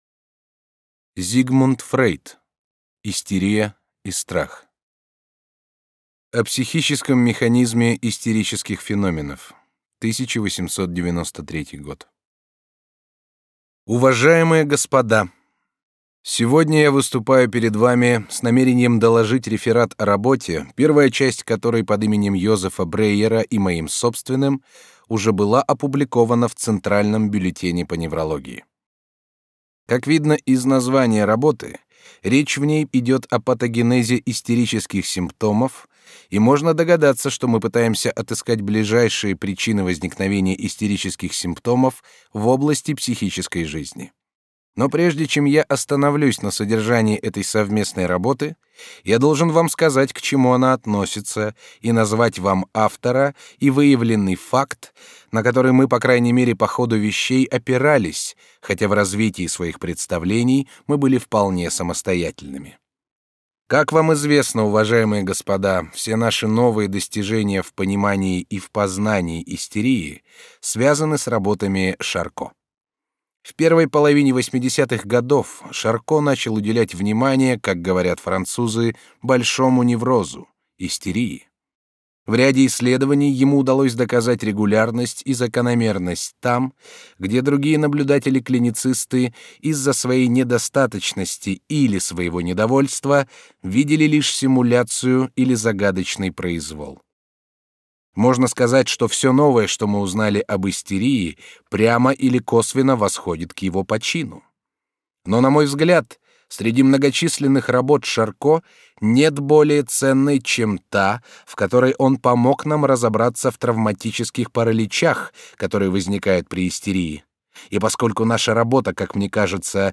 Аудиокнига Истерия и страх | Библиотека аудиокниг
Прослушать и бесплатно скачать фрагмент аудиокниги